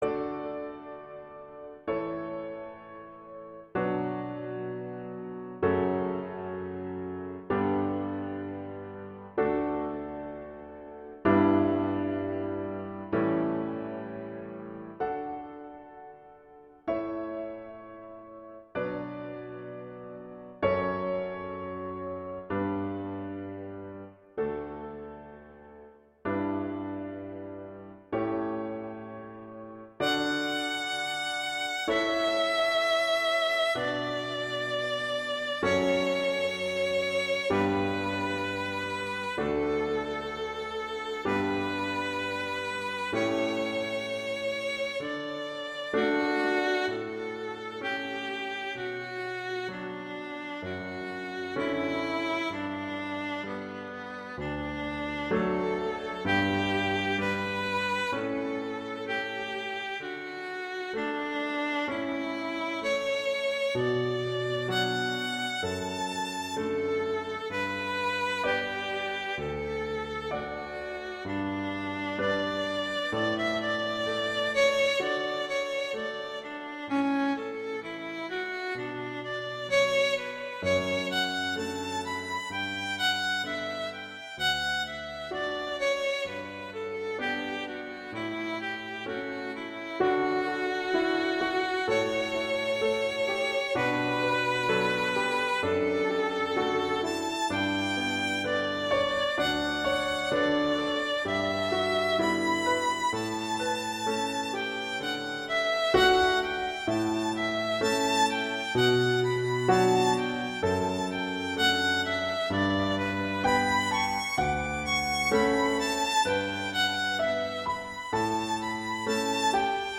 classical, wedding, traditional, easter, festival, love
D major